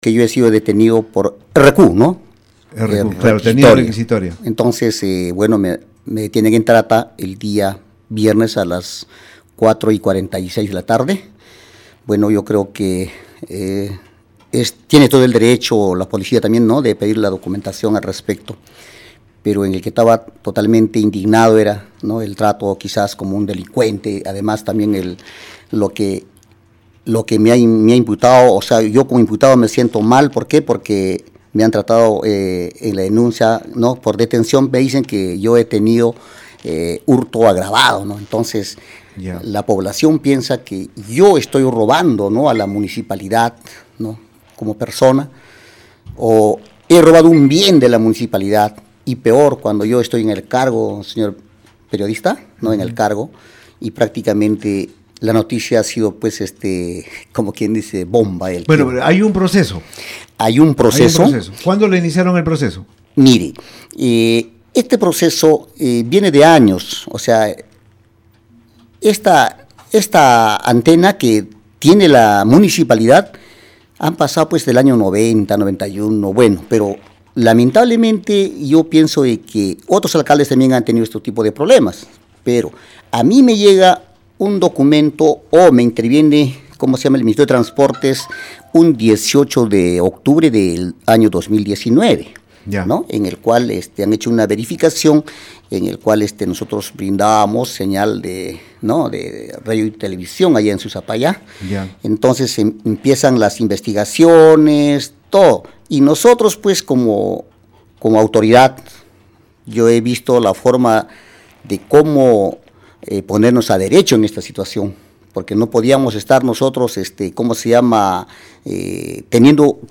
La autoridad edil y docente aclaró en Radio Uno que, en ningún momento afectó las arcas municipales siendo el caso que le atañe un tema de uso sin autorización de espectro electromagnético para transmisiones de radio y televisión que es controlado por el Ministerio de Transportes y Comunicaciones (MTC) y que incluso esto deriva de irresponsabilidad de gestiones anteriores.